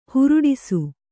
♪ huruḍisu